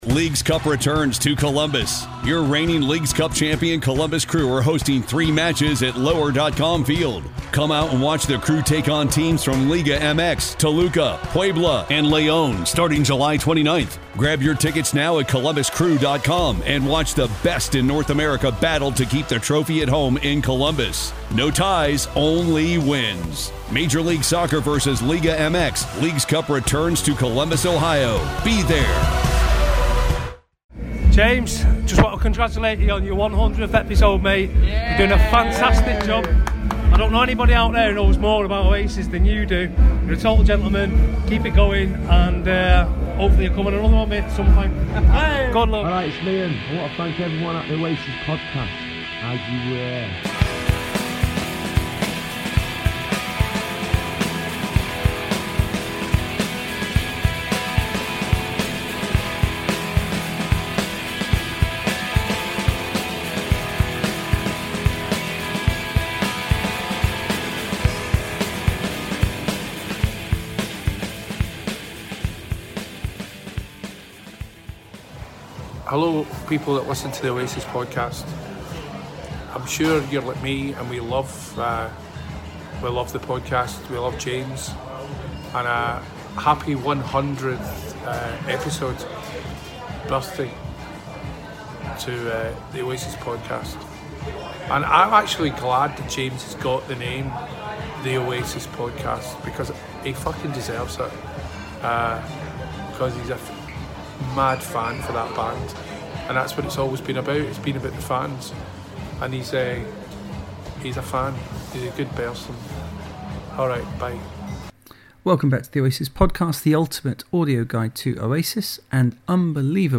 Today's episode features our recent live event in Glasgow with an interview with Tony McCarroll and the discussions I had with the other good people of Glasgow. I also have had some great messages congratulating me on episode 100 so they are in there.